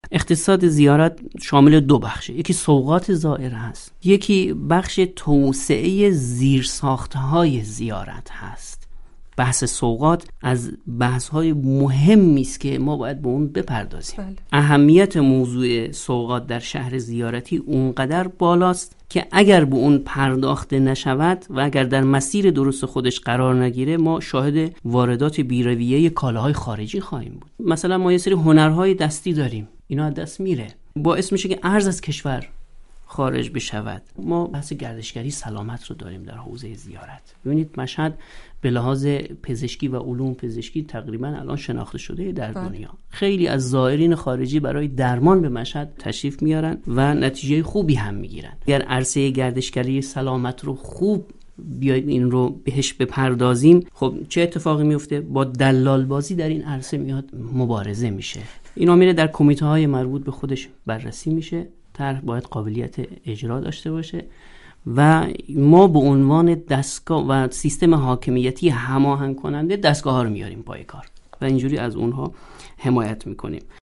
گفتگوی ویژه خبری رادیو زیارت